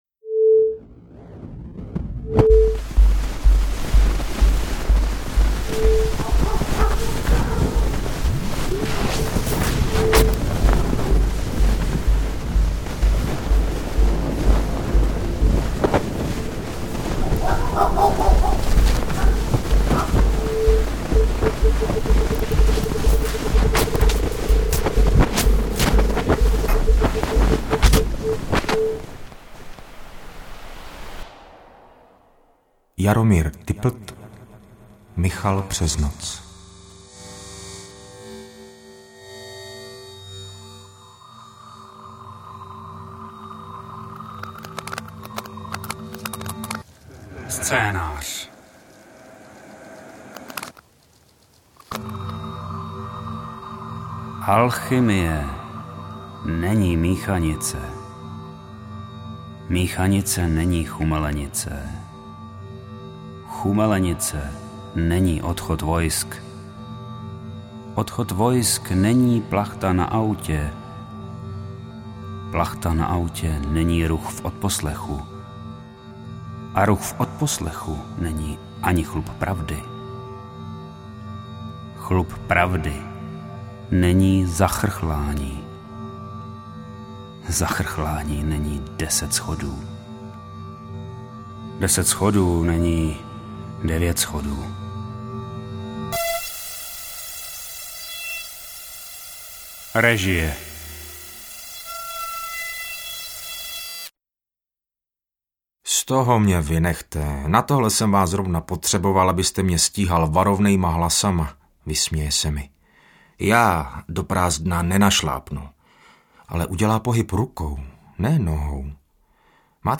AudioKniha ke stažení, 5 x mp3, délka 45 min., velikost 42,5 MB, česky